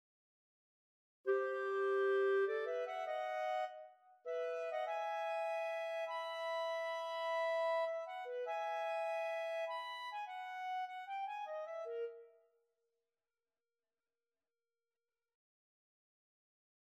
Clarinet: